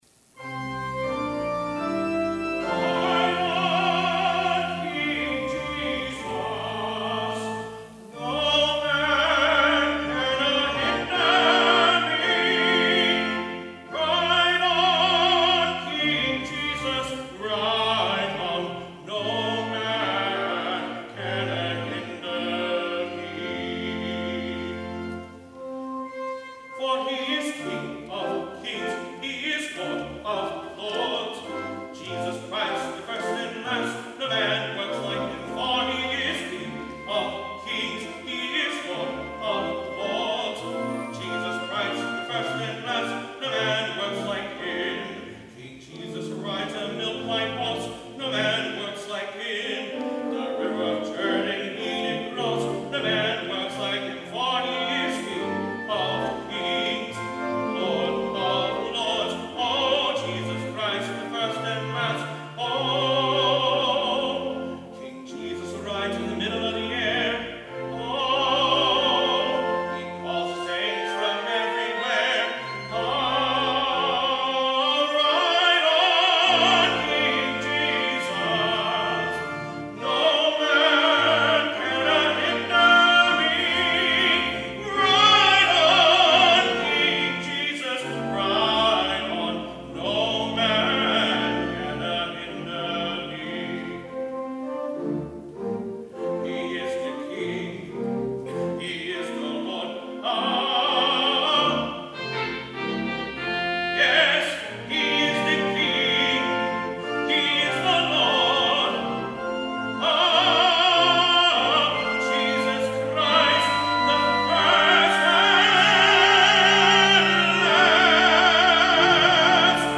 SOLO: Ride on, King Jesus!
tenor